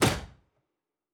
UI Tight 18.wav